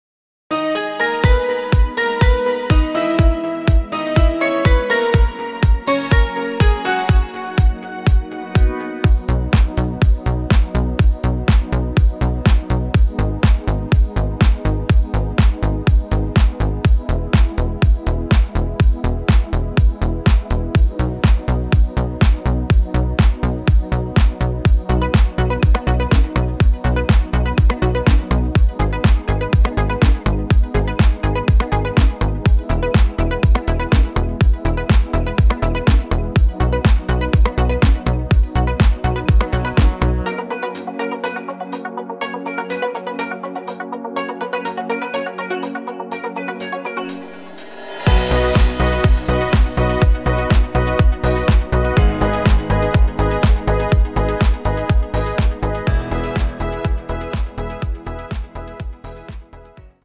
Italo Song